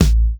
cch_snare_one_shot_low_bassy_rolled.wav